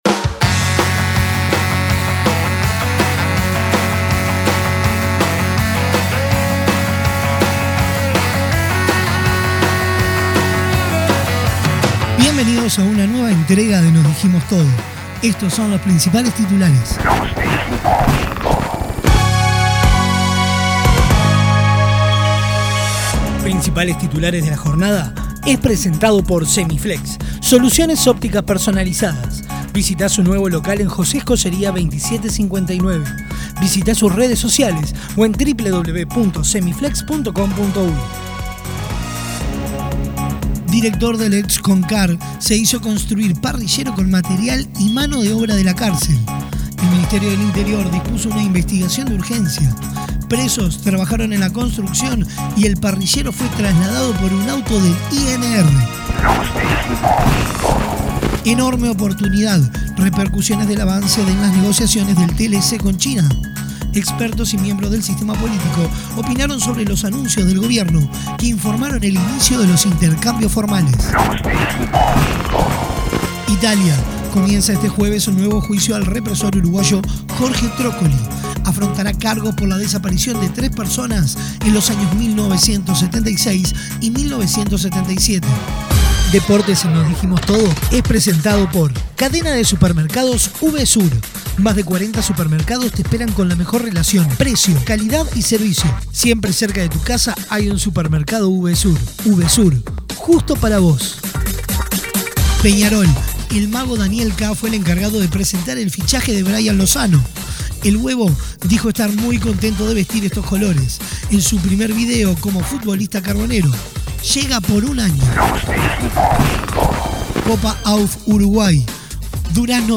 Resumen: Principales titulares de Uruguay y el mundo, con lo mejor del rock nacional